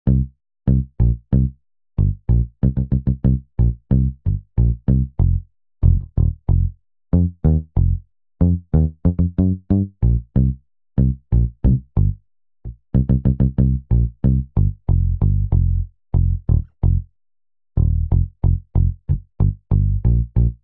08 bass A.wav